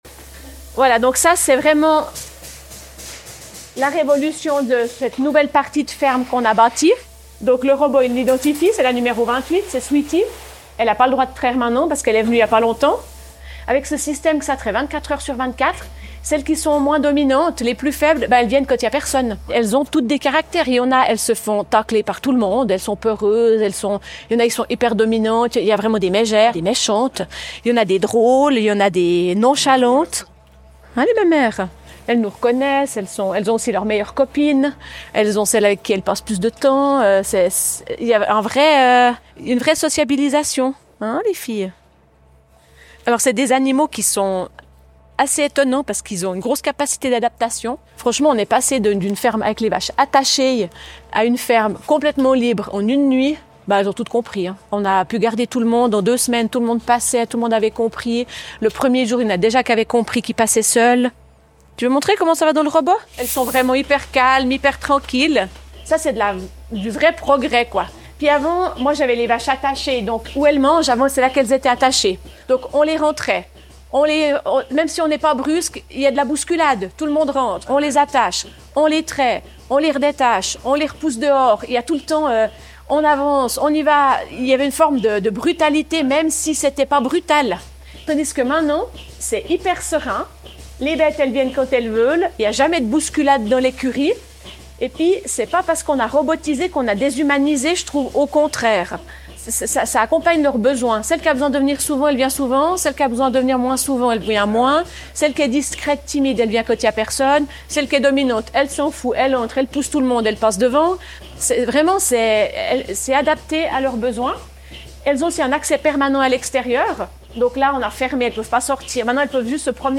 Témoignage audio